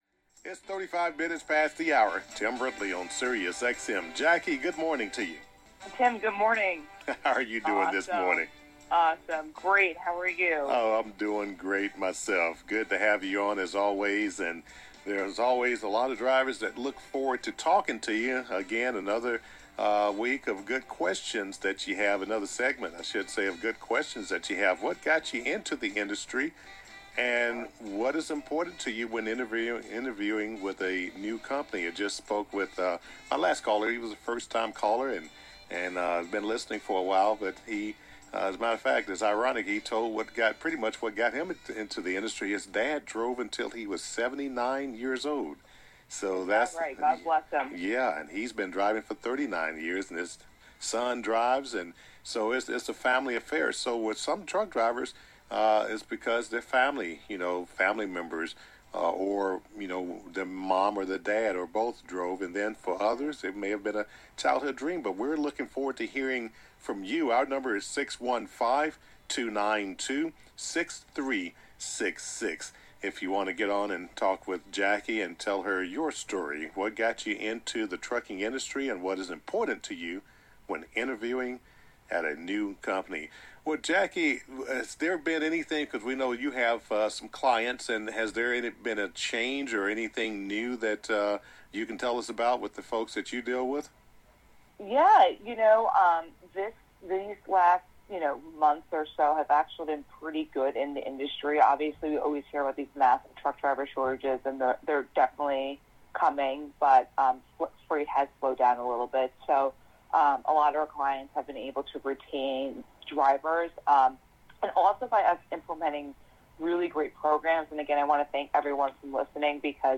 Drivers call in to share their stories.